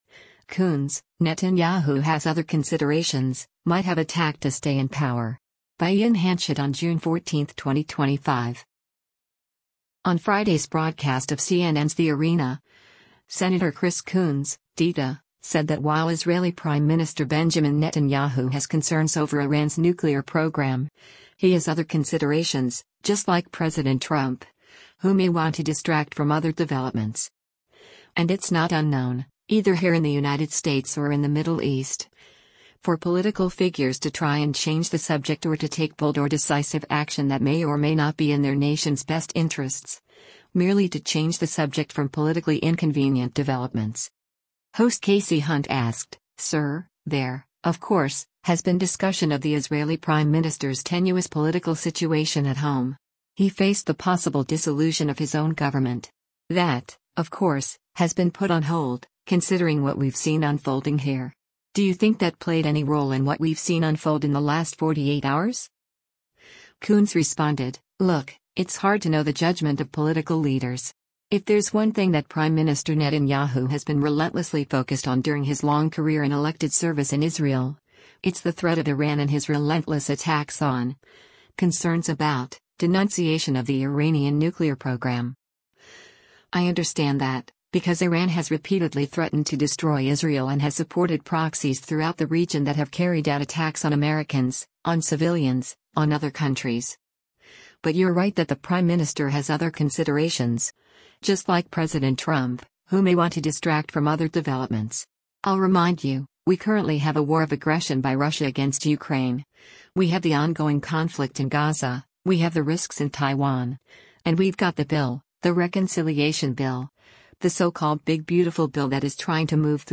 Earlier in the interview, Coons stated that he doesn’t believe that the U.S. gave “explicit approval” for the strikes.